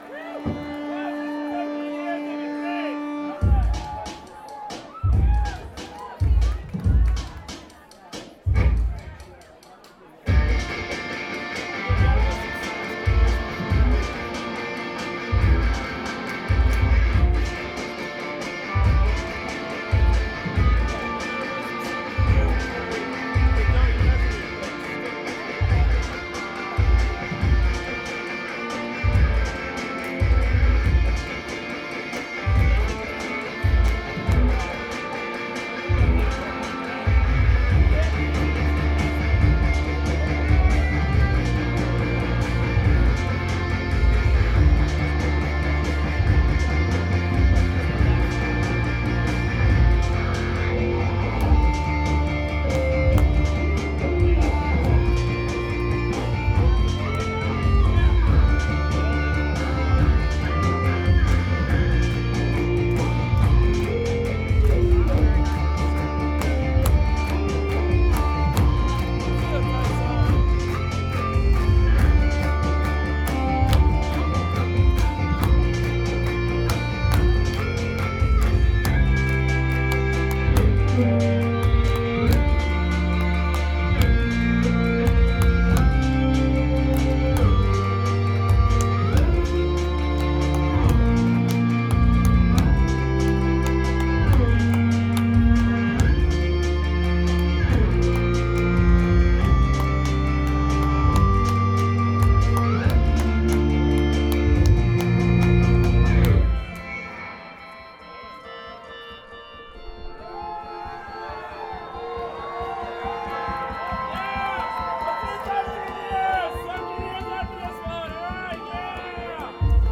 Both recordings turned out ok for a stealth job.
2006-12-01 WWU – Bellingham, WA